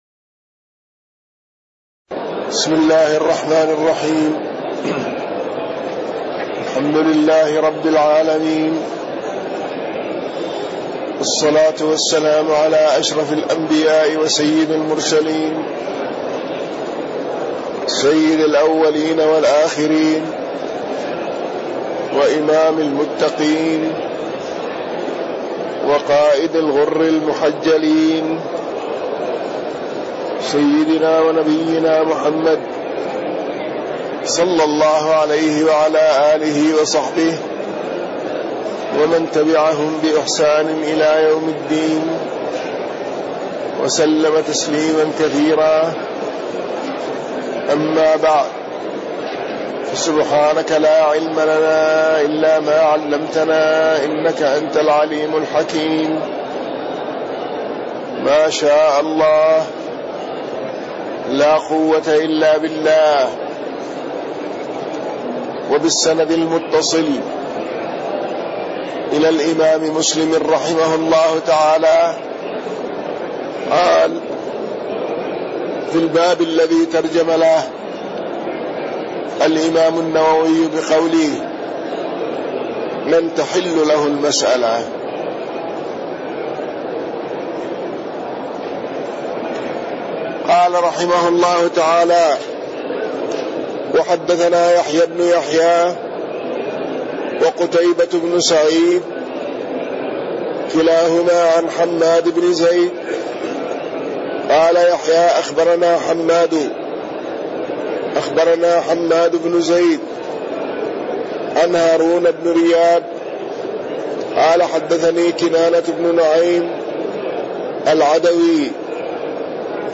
تاريخ النشر ١٦ رمضان ١٤٣٢ هـ المكان: المسجد النبوي الشيخ